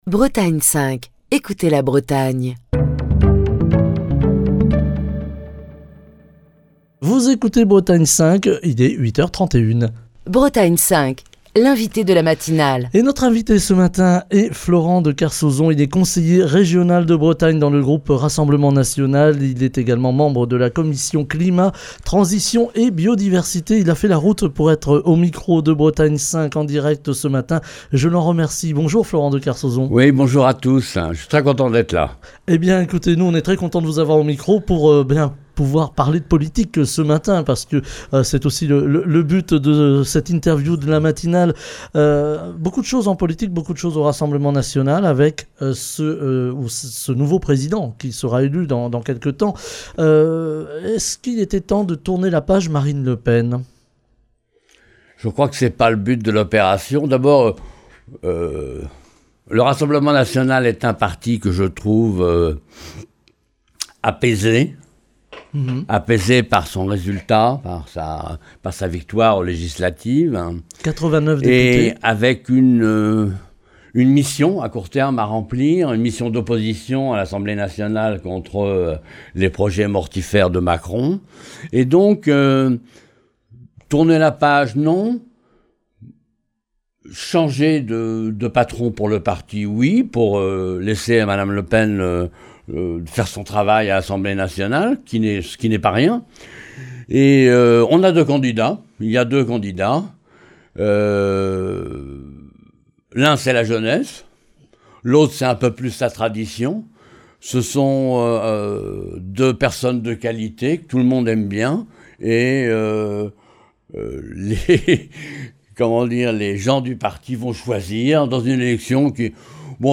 A la Région, le RN se positionne sur des dossiers sensibles comme celui des algues vertes dont nous parlons ce matin avec Florent de Kersauson, conseiller régional de Bretagne dans le groupe Rassemblement national, membre de la commission climat, transitions et biodiversité, qui est l'invité de la matinale de Bretagne 5.